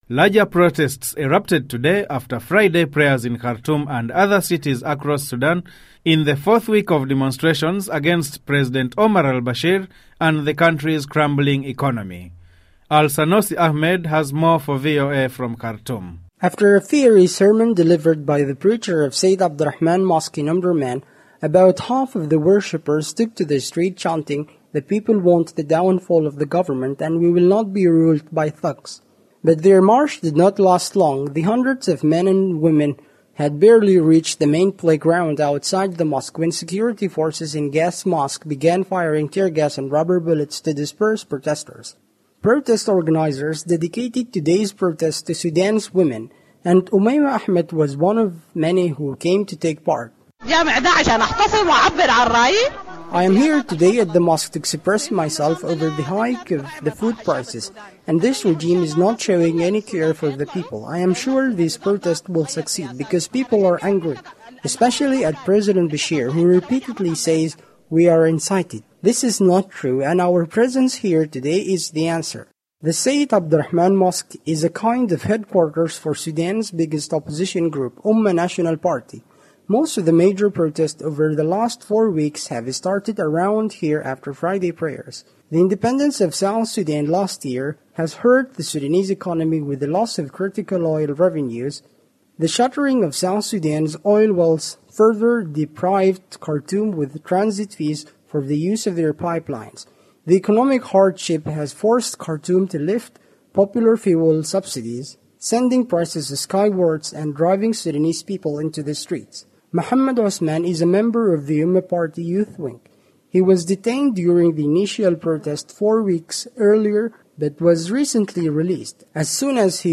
Listen to report on Khartoum protests